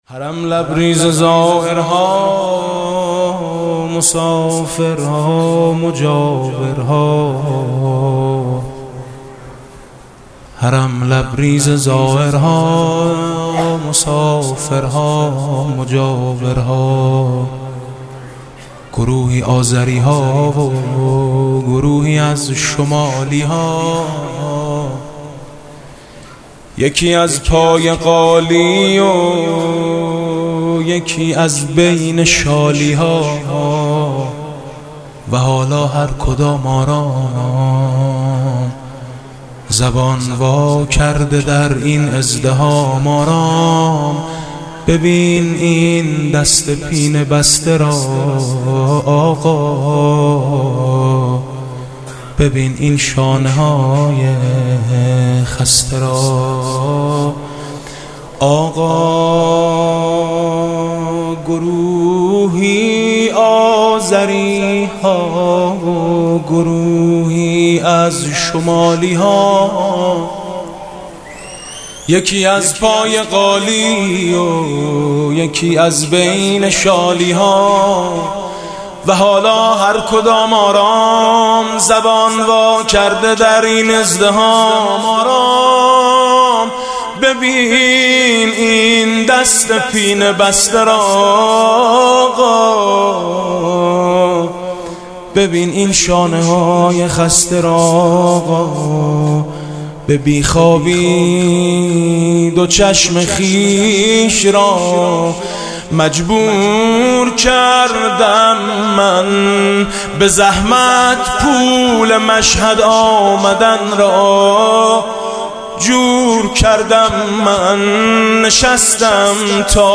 «میلاد امام رضا 1393» مدح: حرم لبریز زائرها مسافرها مجاورها